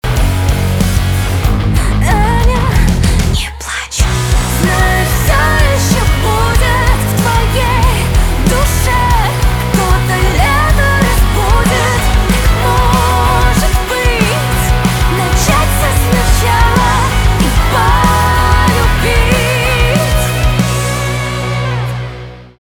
русский рок
гитара , барабаны , чувственные